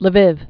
(lə-vĭv, lə-vē) or L'vov or Lvov (lə-vôv, -vôf)